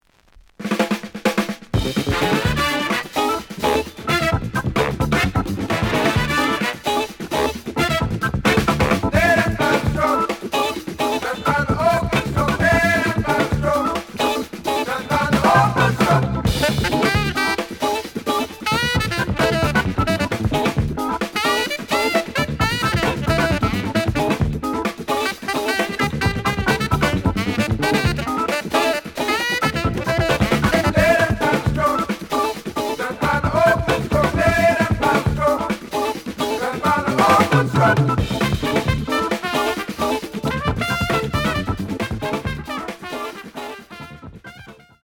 The audio sample is recorded from the actual item.
●Genre: Funk, 70's Funk
B side plays good.)